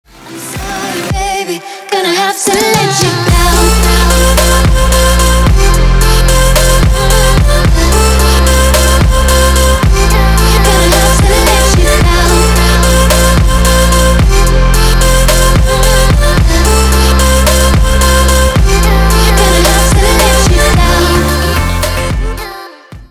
• Качество: 320, Stereo
Electronic
красивый женский голос
future bass